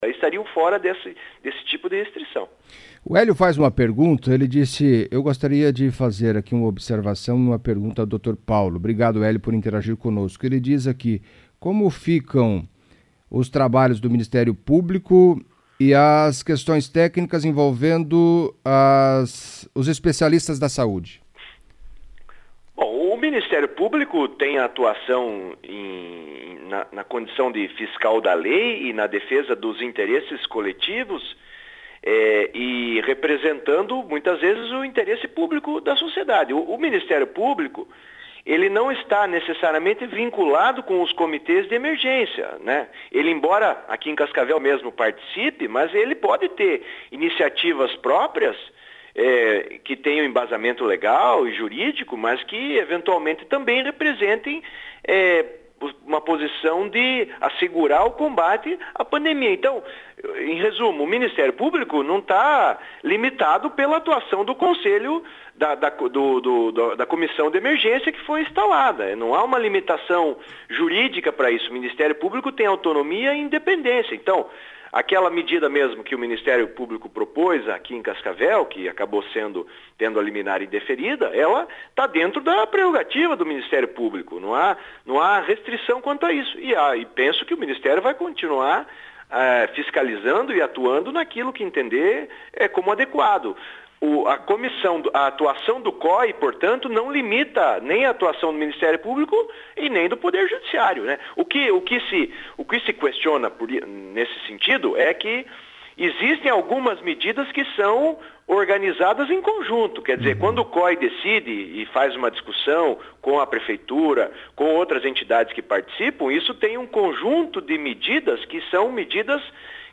A CBN Cascavel ouviu o advogado